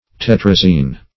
Search Result for " tetrazine" : The Collaborative International Dictionary of English v.0.48: Tetrazine \Tet*raz"ine\, n. Also -in \-in\ .
tetrazine.mp3